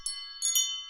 tbd-station-14/Resources/Audio/Effects/Footsteps/bells1.ogg at d1661c1bf7f75c2a0759c08ed6b901b7b6f3388c
bells1.ogg